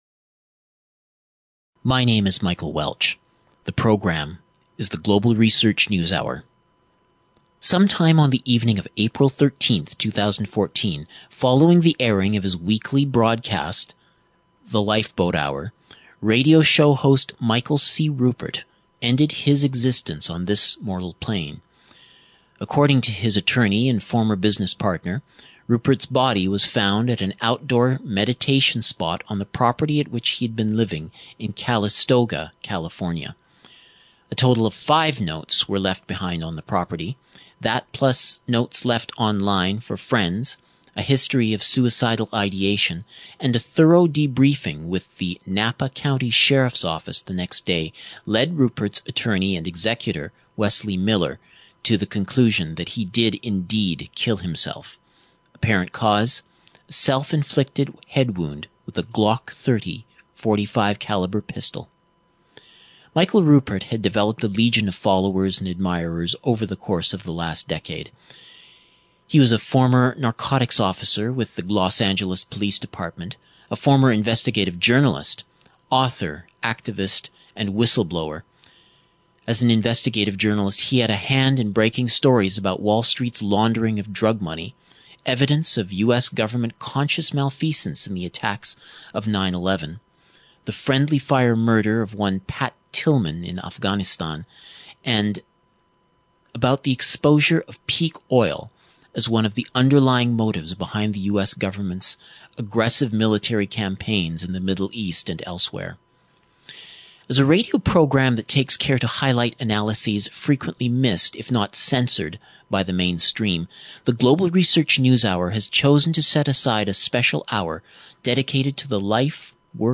Guest interviews look back on the Life and Legacy of Michael Ruppert